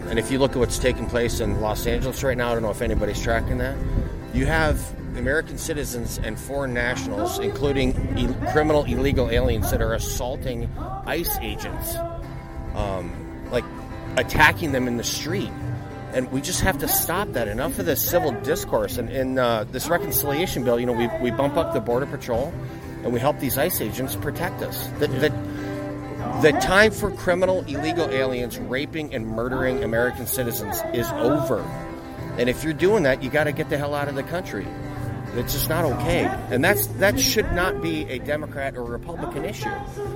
Wisconsin 3rd District Congressman Derek Van Orden made an appearance at the Grant County Dairy Breakfast. The Republican from Prairie du Chien addressed the protests taking place in California.